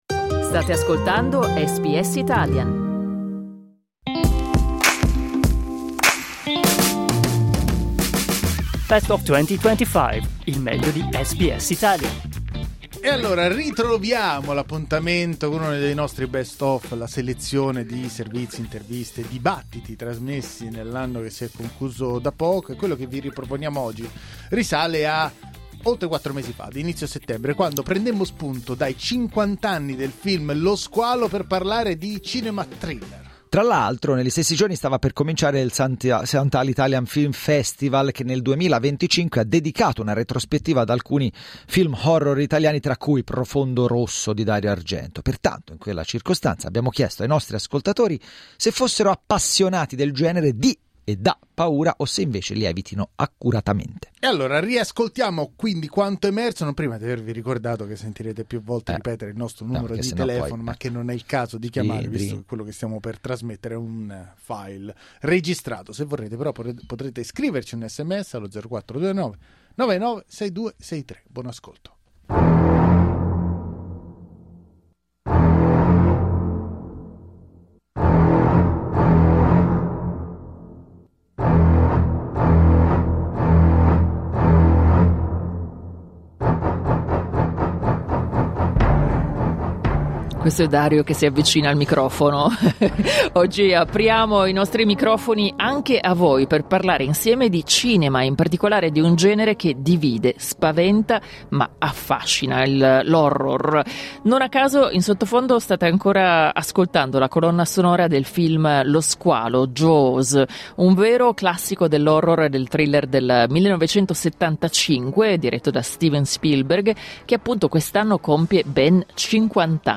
Cosa ci spinge a vedere film che giocano sulle nostre paure? In occasione del St Ali Italian Film Festival del 2025 ne parlammo in questo dibattito.